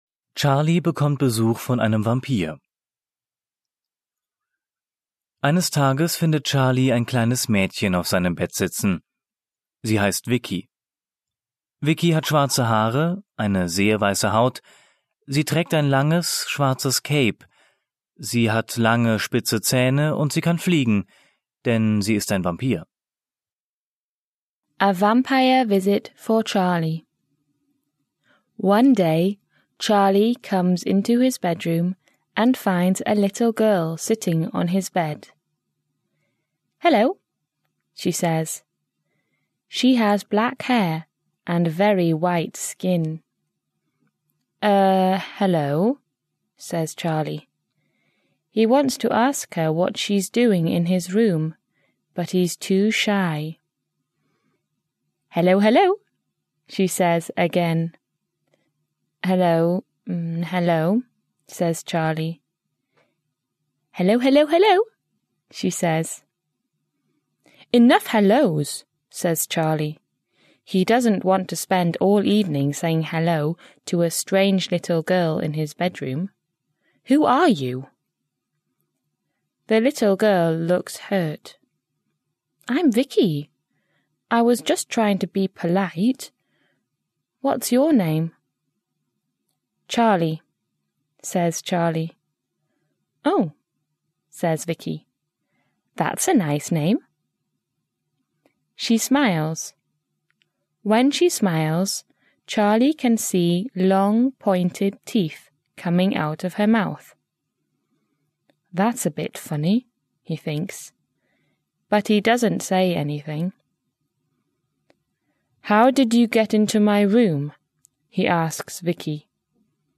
Vor jeder englischen Geschichte ist eine kurze Einführung auf Deutsch gegeben, die den Inhalt der Geschichte kurz zusammenfasst. Alle Geschichten sind von Muttersprachlern gesprochen.